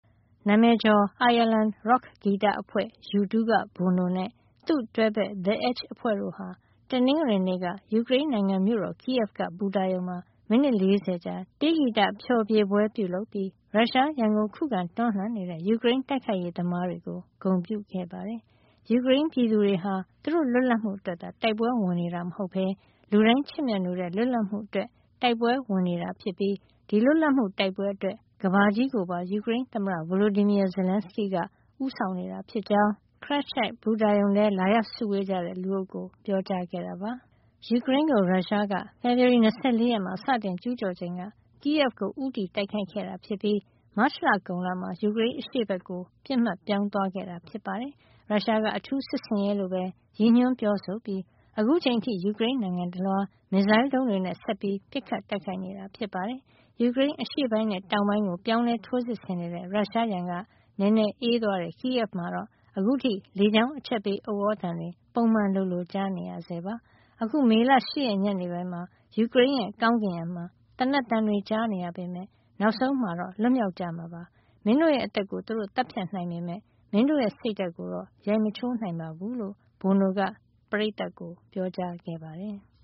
နာမည်ကျော် အိုင်ယာလန်ရော့ခ်ဂီတအဖွဲ့ U2 က Bono နဲ့ သူ့တွဲဘက် The Edge ဂစ်တာသမား တို့ဟာ တနင်္ဂနွေနေ့က ယူကရိန်းနိုင်ငံ မြို့တော် Kyiv က ဘူတာရုံမှာ မိနစ် ၄၀ ကြာ အခမဲ့ တေးဂီတဖျော်ဖြေပွဲ ပြုလုပ်ပြီး ရုရှားရန်ကို ခုခံတွန်းလှန်နေတဲ့ ယူကရိန်း တိုက်ခိုက်ရေးသမားတွေကို ဂုဏ်ပြုခဲ့ပါတယ်။